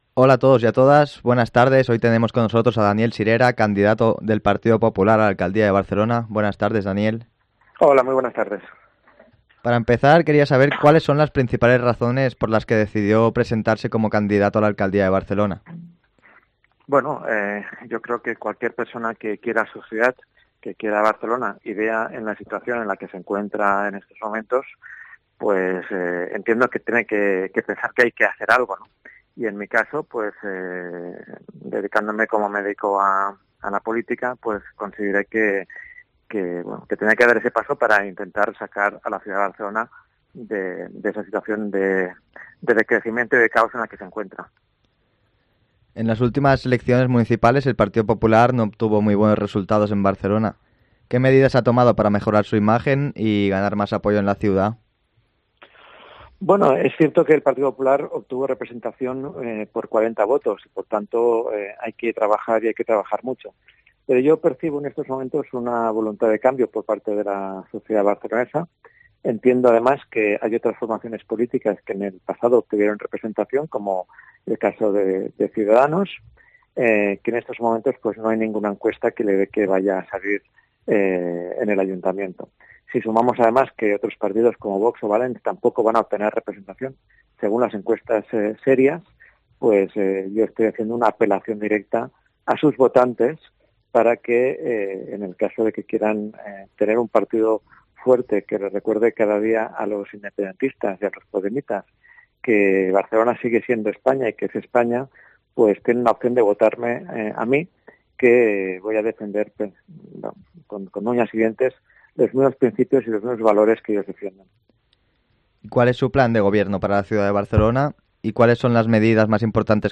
Entrevista a Daniel Sirera, candidato del Partido Popular a la Alcaldía de Barcelona